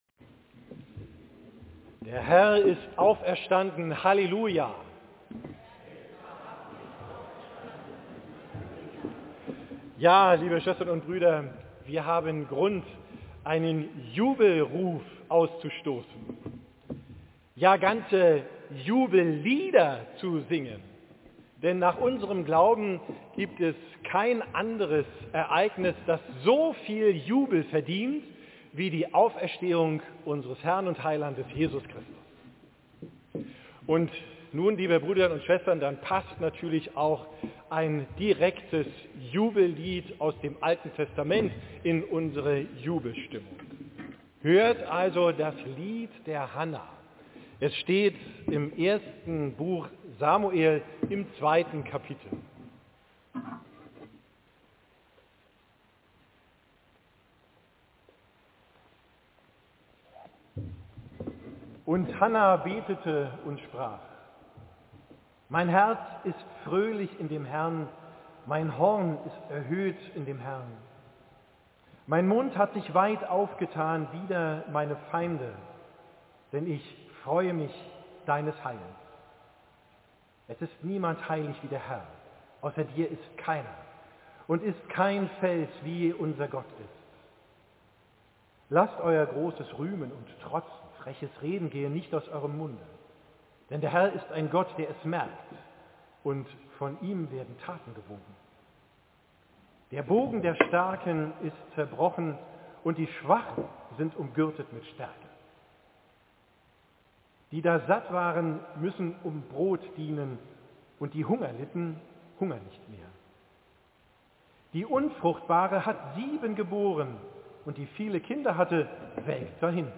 Predigt am Ostersonntag, 31.